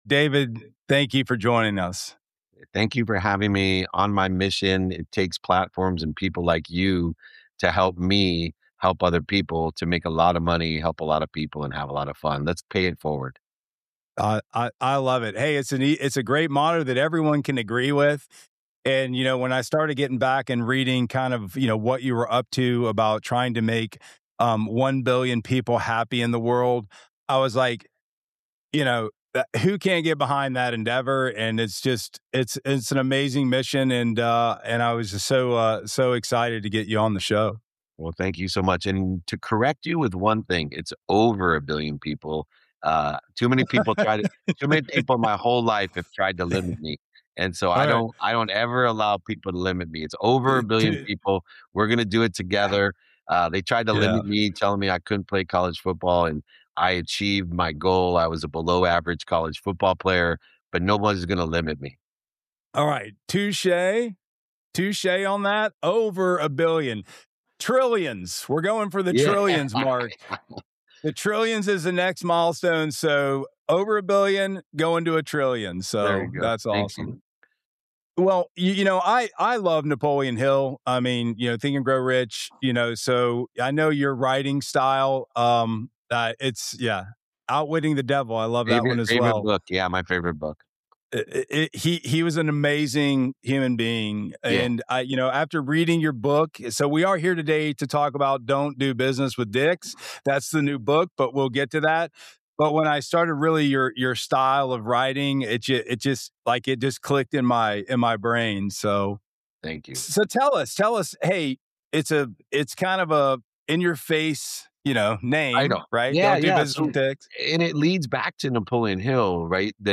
We also get into building a community of leaders, not followers, and how empowering others creates exponential impact over time. It’s a conversation about optimism, accountability, consistency, and removing the interference between you and your full pote